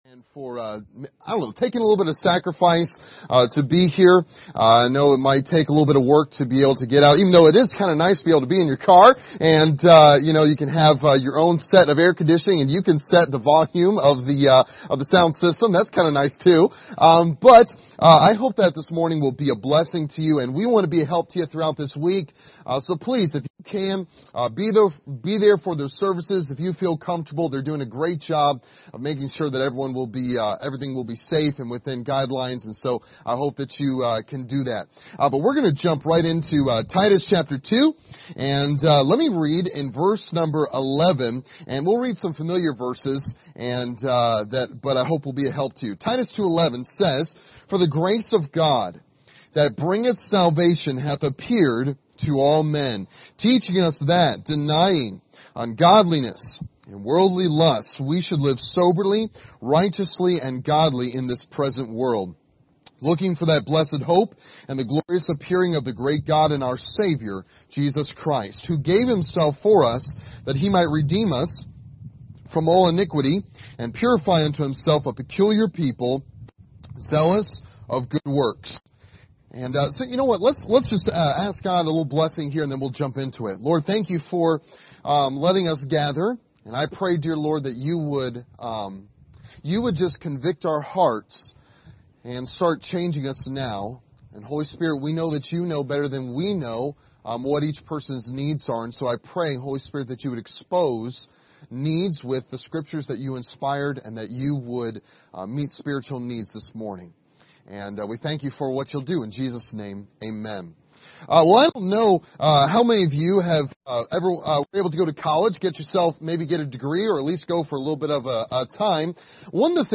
Passage: Titus 2:11-14 Service Type: Sunday Morning